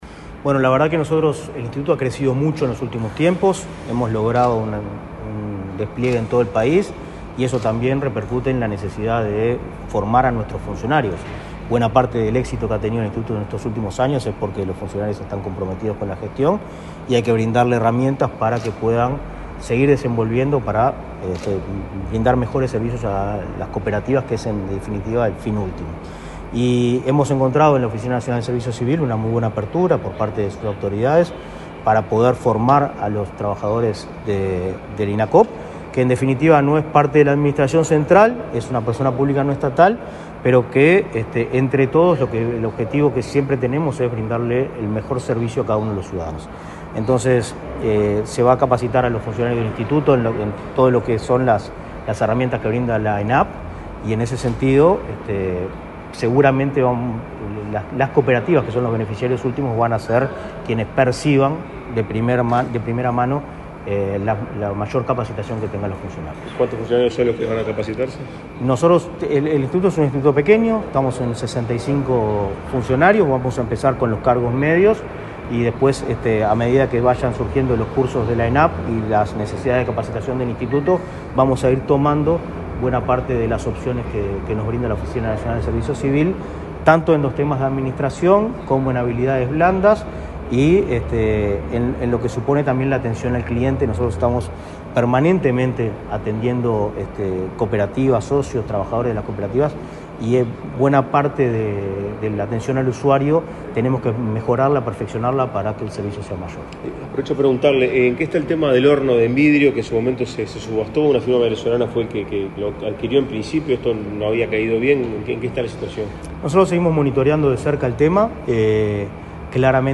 Declaraciones del presidente de Inacoop, Martín Fernández
Declaraciones del presidente de Inacoop, Martín Fernández 23/04/2024 Compartir Facebook Twitter Copiar enlace WhatsApp LinkedIn El presidente de Inacoop, Martín Fernández, dialogó con la prensa en Torre Ejecutiva, luego de firmar un convenio de cooperación, con el director de la Oficina Nacional de Servicio Civil (ONSC), Ariel Sánchez.